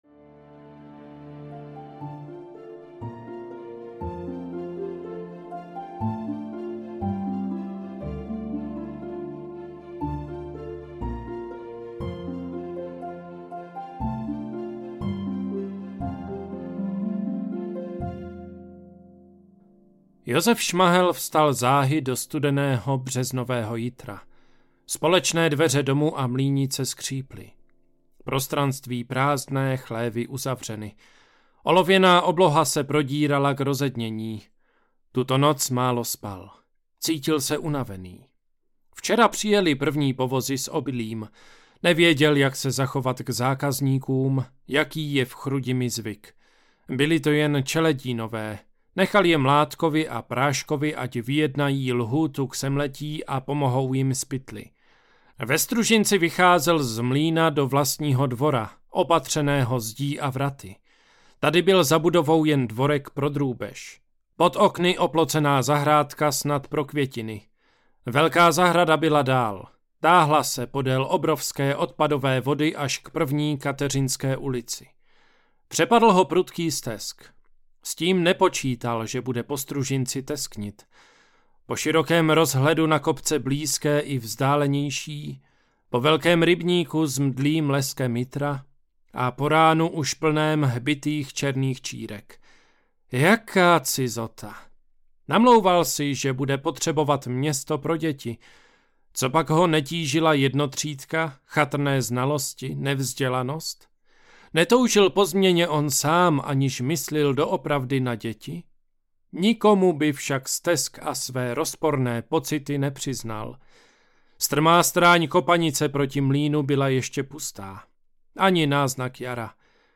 Hlasy mých otců audiokniha
Ukázka z knihy